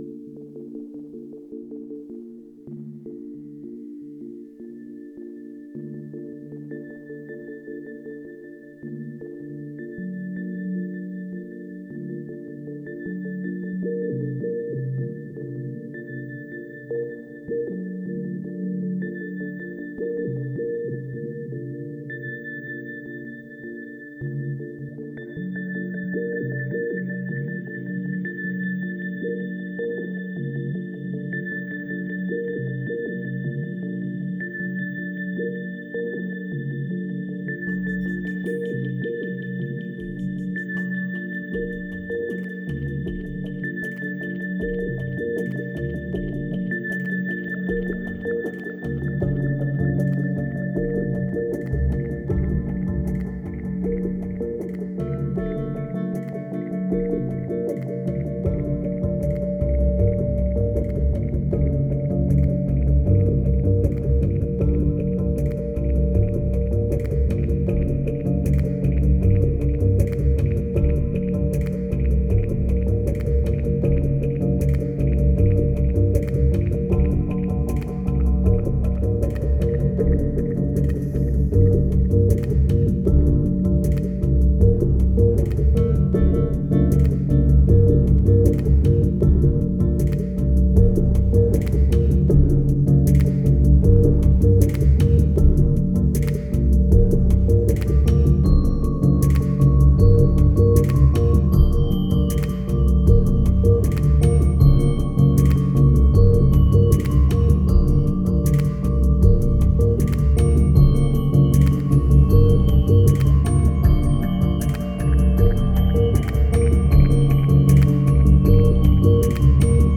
2561📈 - 92%🤔 - 78BPM🔊 - 2014-05-01📅 - 876🌟